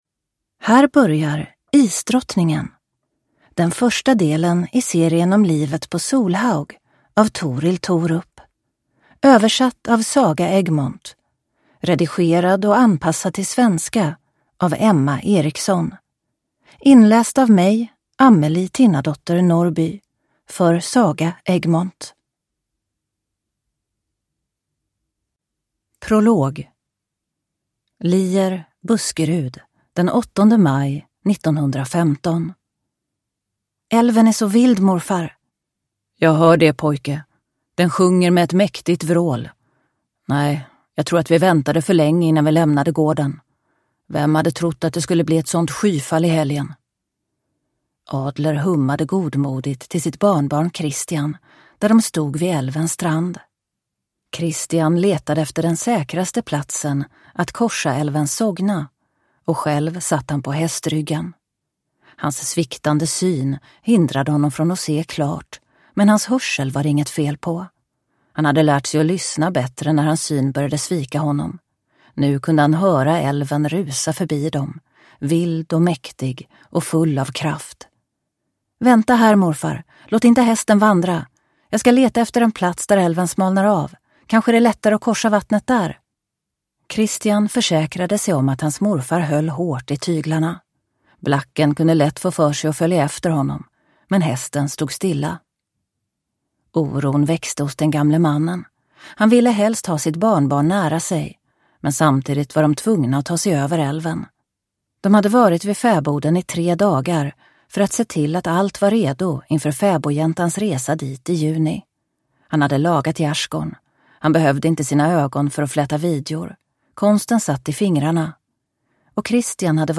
Isdrottningen (ljudbok) av Torill Thorup